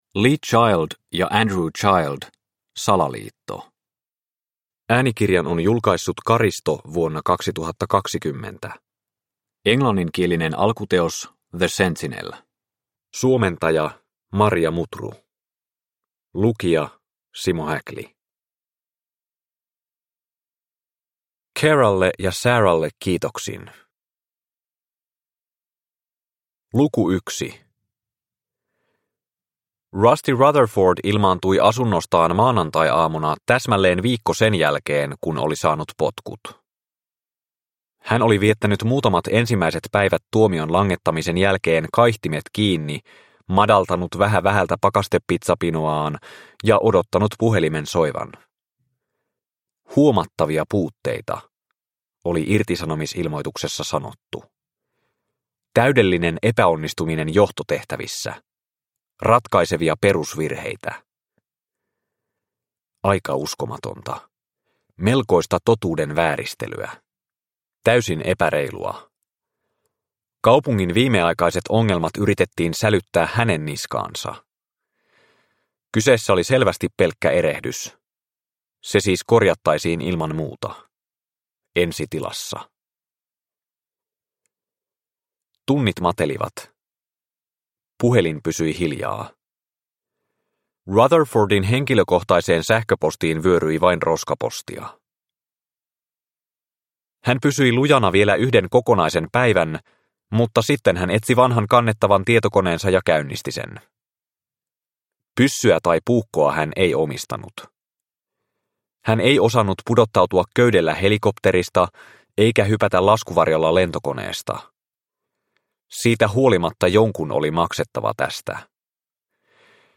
Salaliitto – Ljudbok – Laddas ner